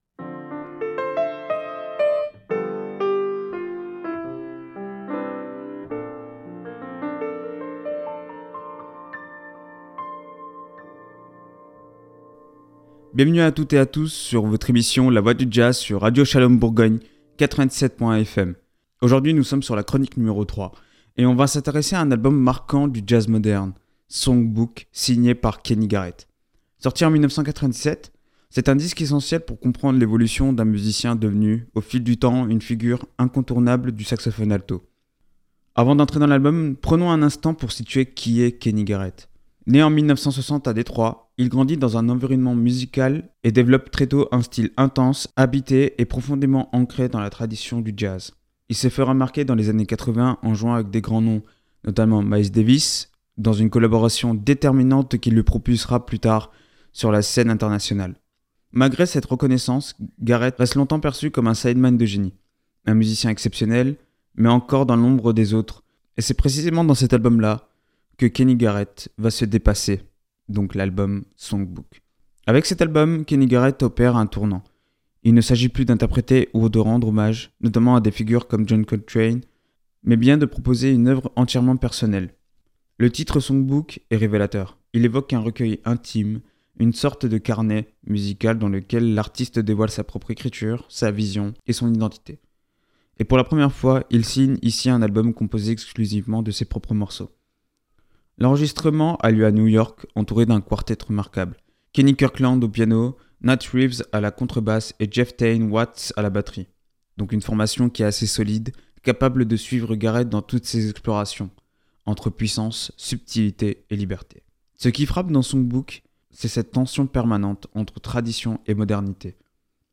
Un jazz à la fois puissant et introspectif